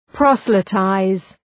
Προφορά
{‘prɒsəlı,taız}